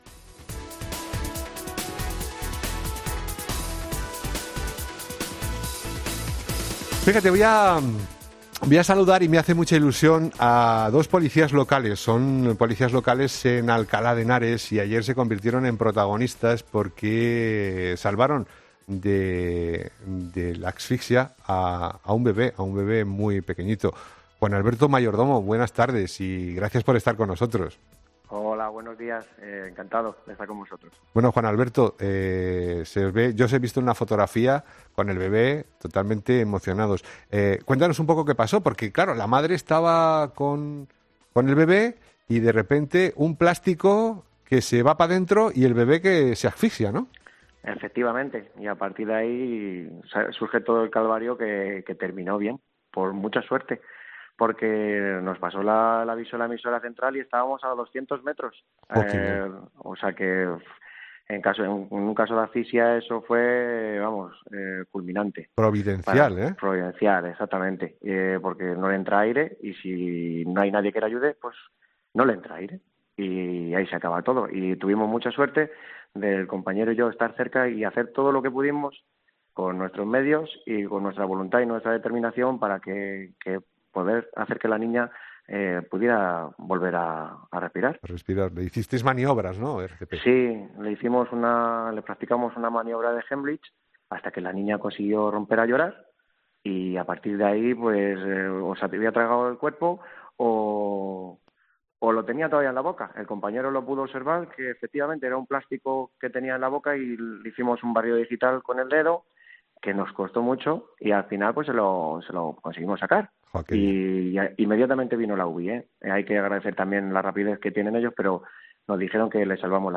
en 'Herrera en COPE' hemos hablado con ellos